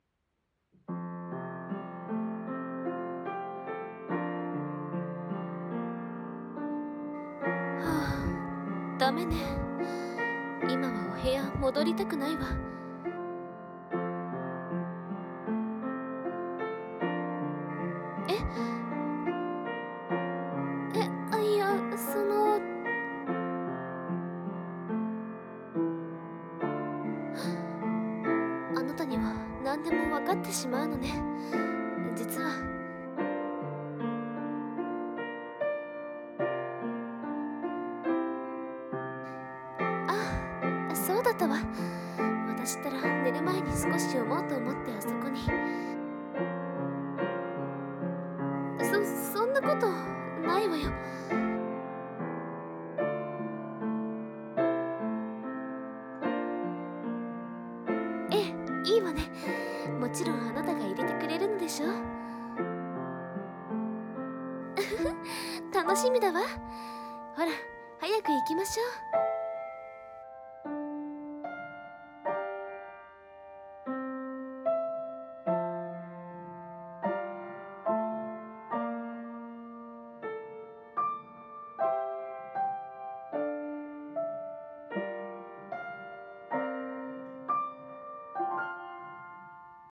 声劇 【執事とお嬢様と執事 3】 執事×お嬢様